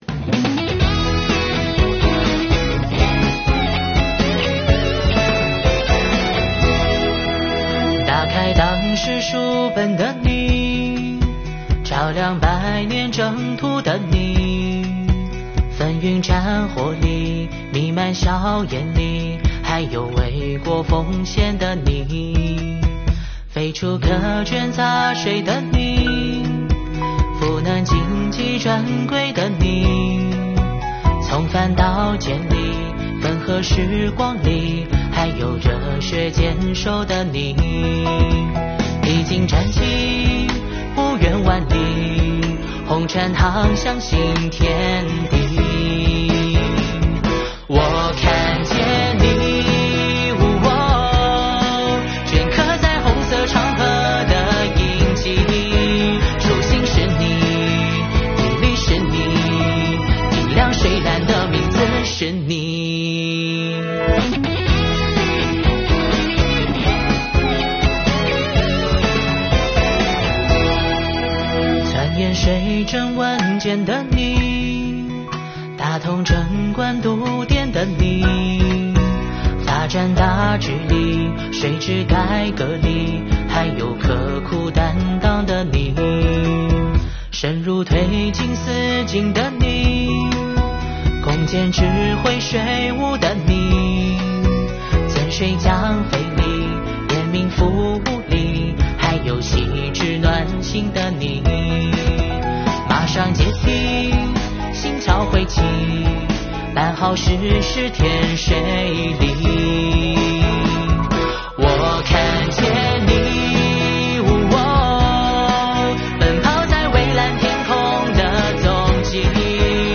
这首由税务人员原创词曲、倾情打造的MV